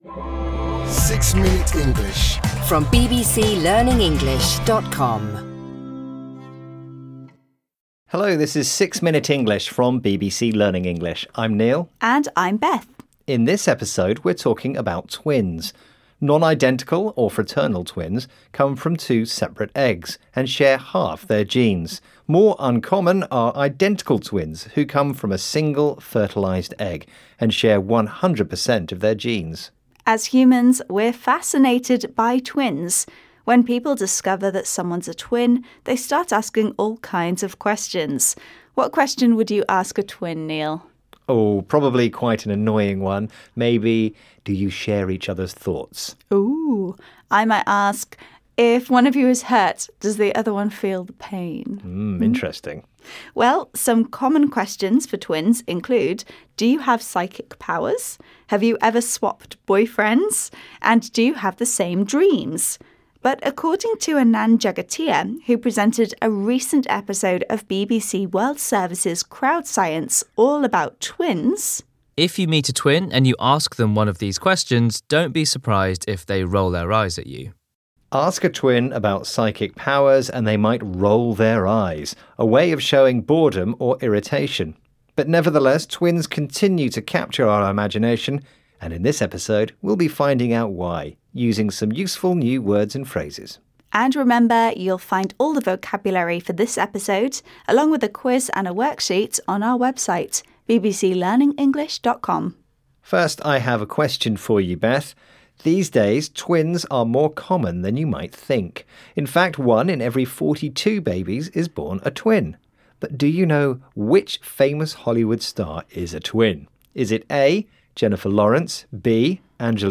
گویندگان این پادکست با لهجه انگلیسی بریتانیایی (British English) صحبت می‌کنند که منبعی ایده‌آل برای افرادی است که قصد شرکت در آزمون آیلتس دارند.
هر قسمت این پادکست شامل گفت‌وگویی کوتاه و جذاب درباره موضوعات متنوعی است که به زبان ساده و قابل‌فهم ارائه می‌شود تا به شنوندگان در تقویت مهارت‌های شنیداری، مکالمه و یادگیری واژگان جدید کمک کند.